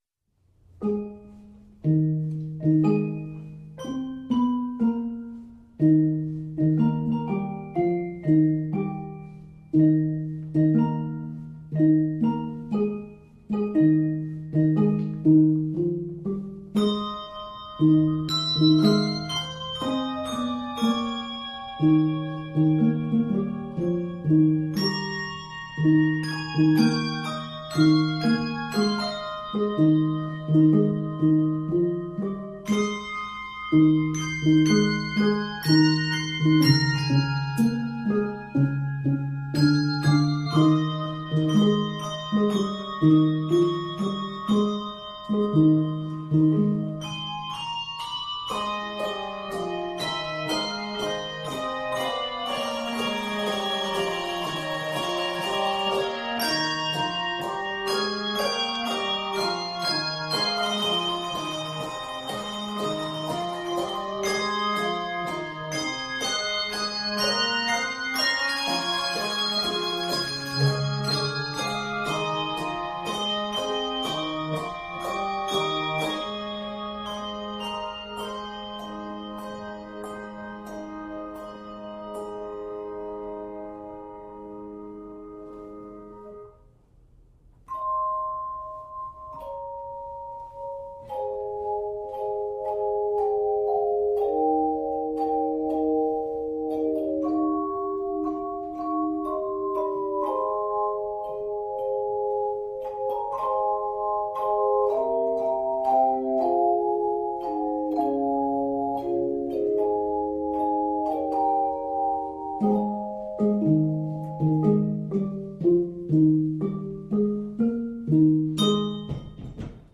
It is scored in Ab Major.